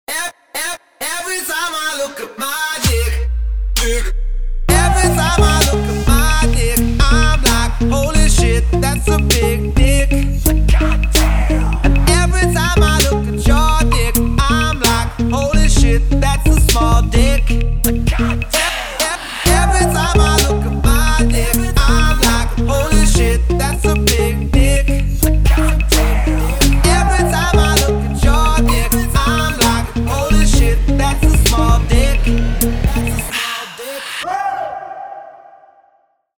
• Качество: 320, Stereo
Хип-хоп
Rap